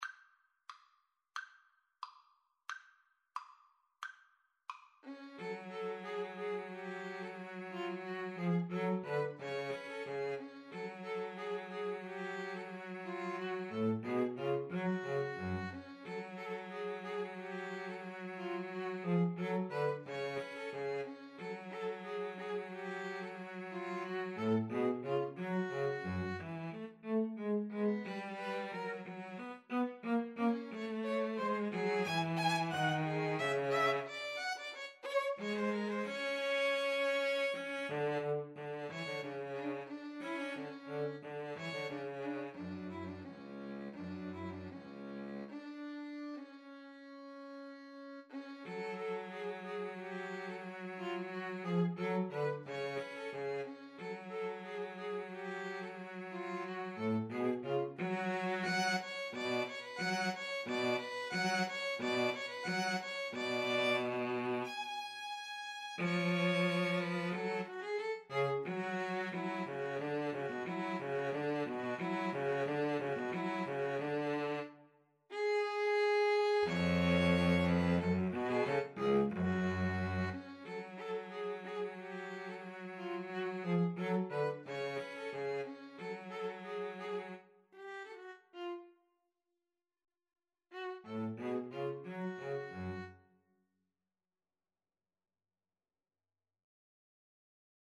Allegretto = 90
Classical (View more Classical String trio Music)